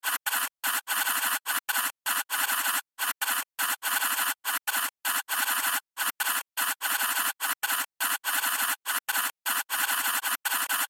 На этой странице собраны звуки землетрясений разной интенсивности: от глухих подземных толчков до разрушительных катаклизмов.
Звук записывающего сейсмографа